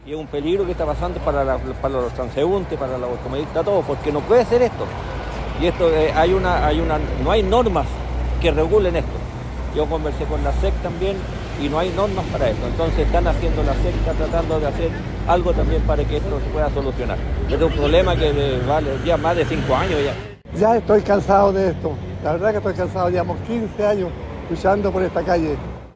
cuna-postes-vecinos.mp3